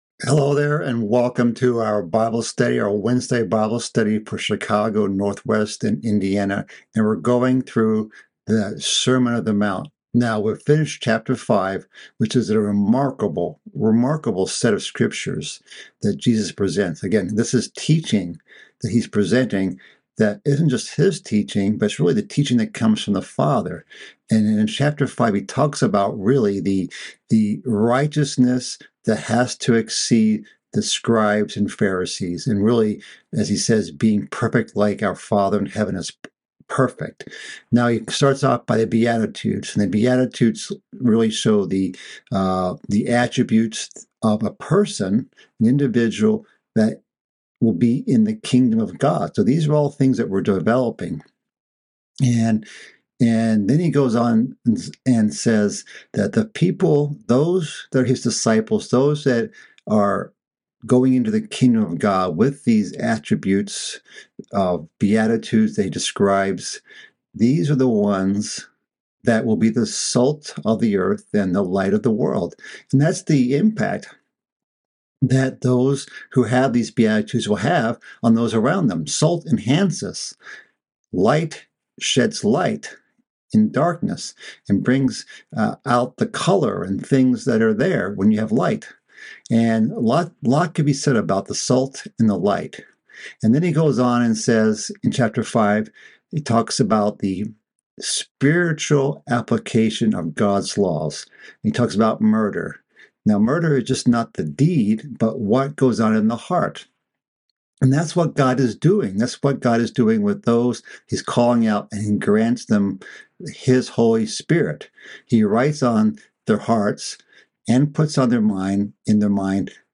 This is part of a mid-week Bible study series covering the sermon on the mount. This study covers the correct mindset behind charitable deeds and prayer.